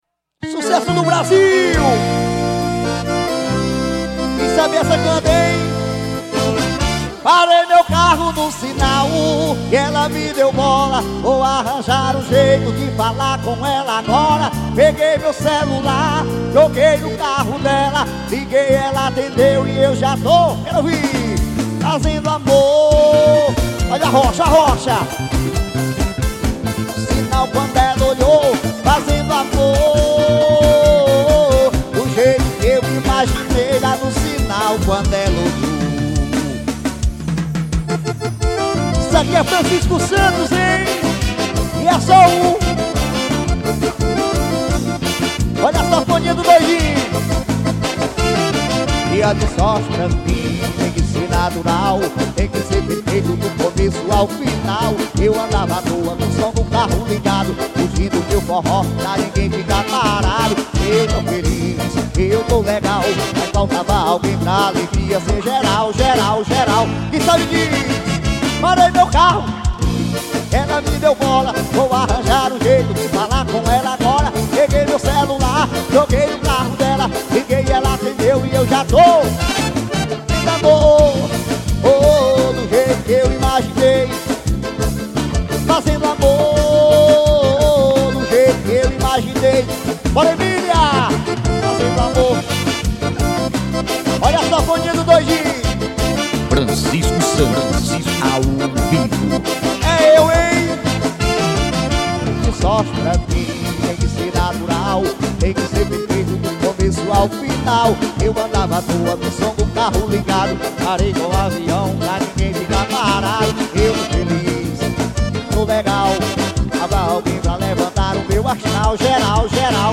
AOVIVO EM INHUMA.